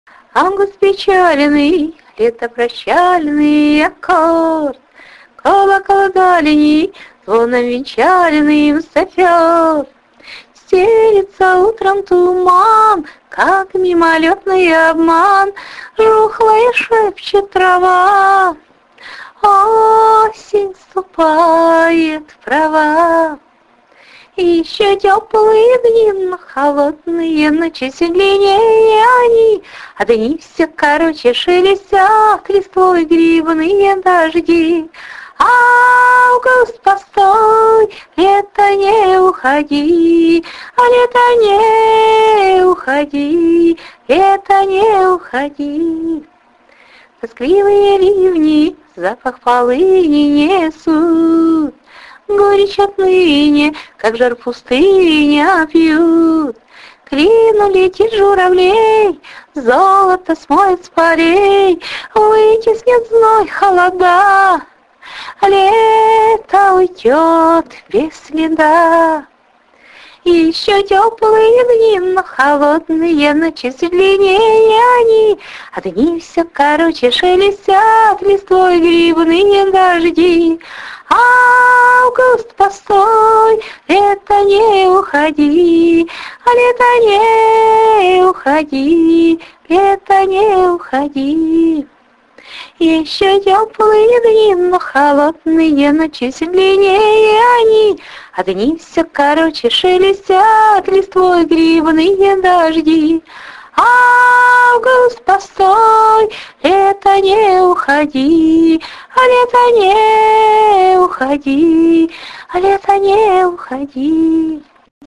почему-то пение напомнило Гребенщикова)в хорошем смысле.